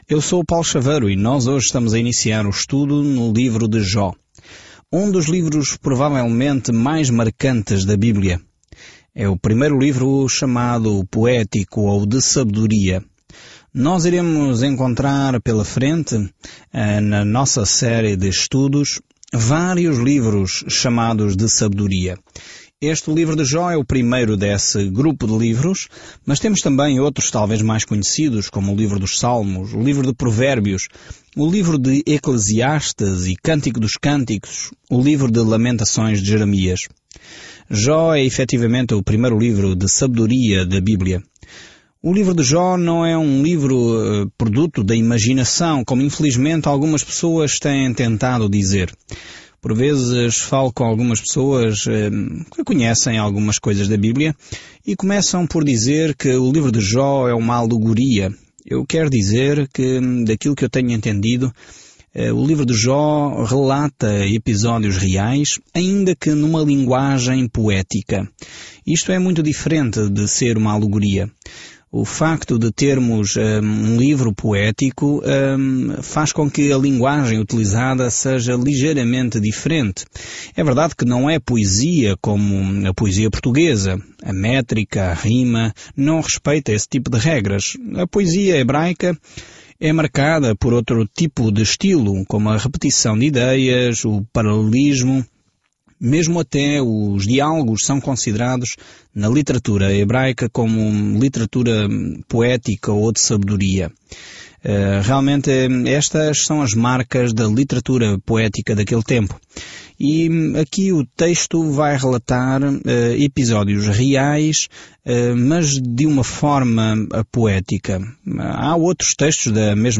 Escritura JOB 1:1-5 Iniciar este Plano Dia 2 Sobre este plano Neste drama do céu e da terra, encontramos Jó, um homem bom, a quem Deus permitiu que Satanás atacasse; todo mundo tem tantas perguntas sobre por que coisas ruins acontecem. Viaje diariamente por Jó enquanto ouve o estudo em áudio e lê versículos selecionados da palavra de Deus.